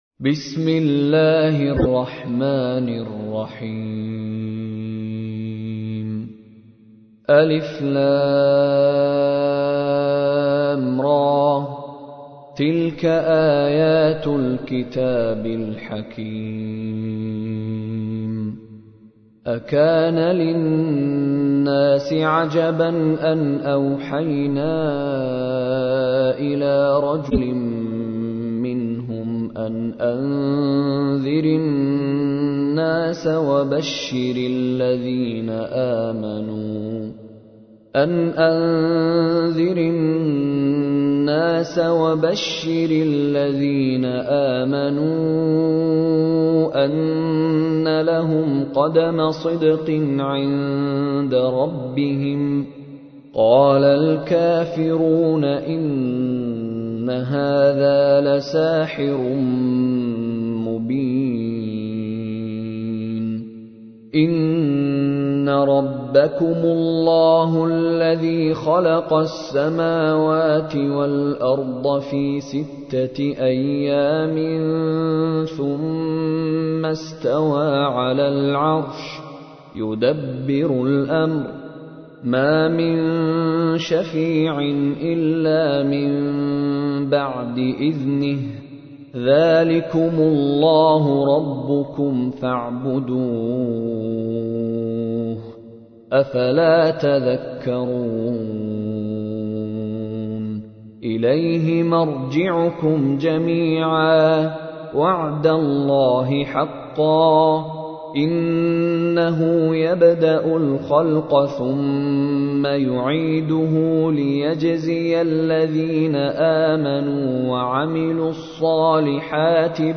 تحميل : 10. سورة يونس / القارئ مشاري راشد العفاسي / القرآن الكريم / موقع يا حسين